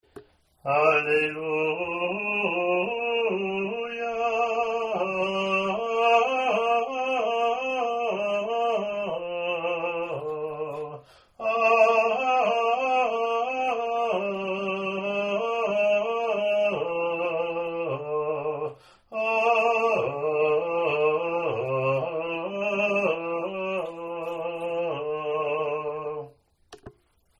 hwes-allelluia-gm.mp3